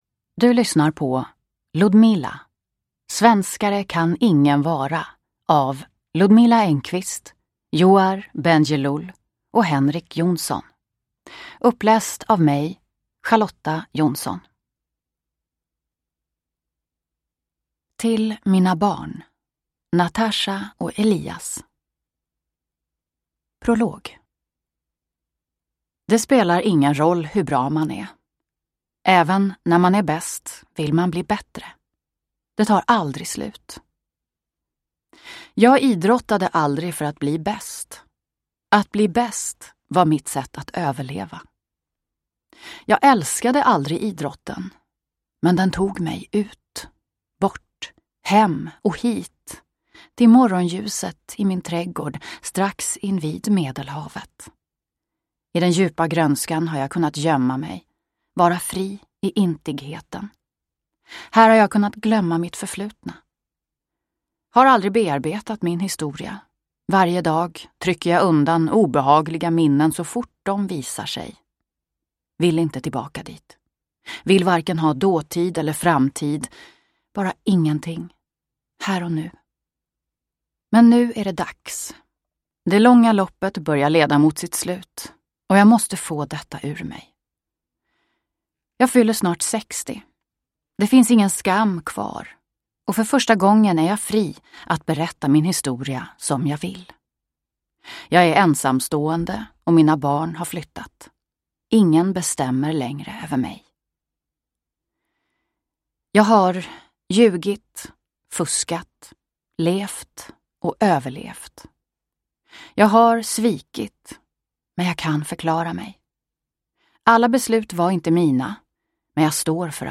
Ludmila : svenskare kan ingen vara – Ljudbok